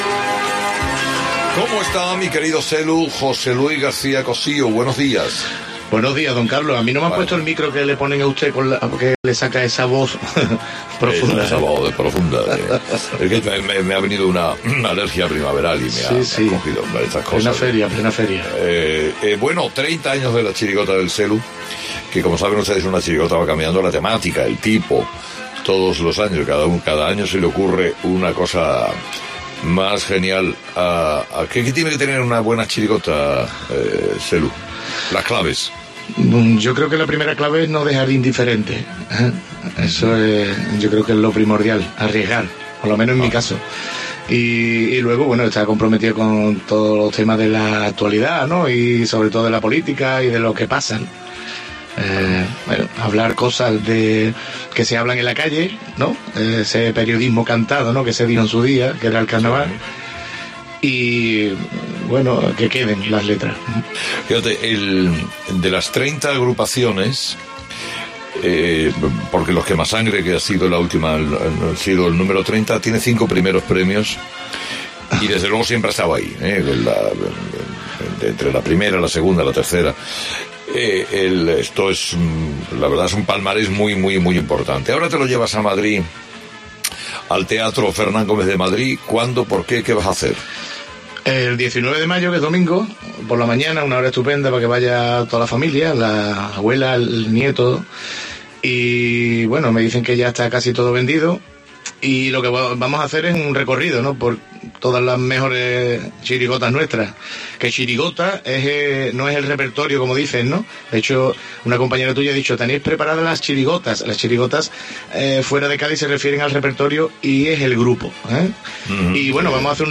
Carlos Herrera entrevista en 'Herrera en COPE' al Selu. El próximo 19 de mayo estará con sus 30 años de chirigotas en el Teatro Fernán Gómez de Madrid